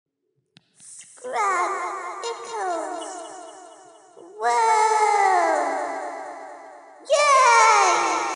mouse voice!!!!!